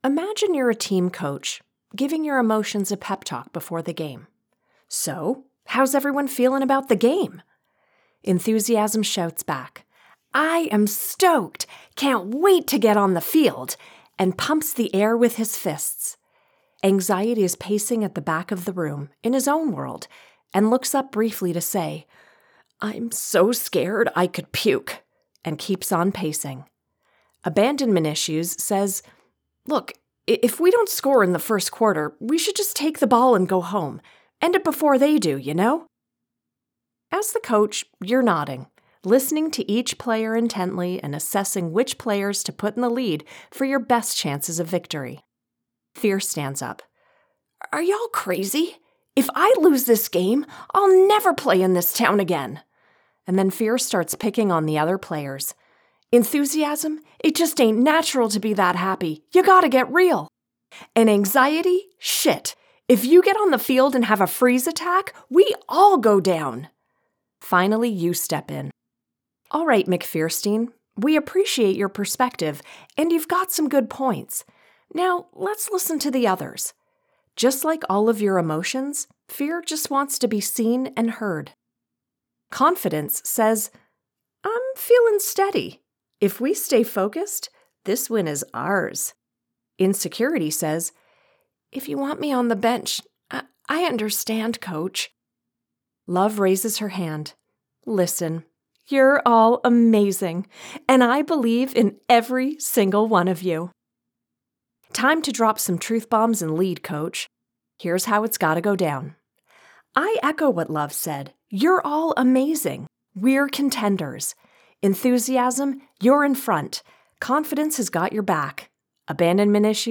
Nonfiction Audiobook (Author Danielle LaPorte)
🎙 Broadcast-quality audio
(RODE NT1 Signature Mic + Fully Treated Studio)
Middle Aged Female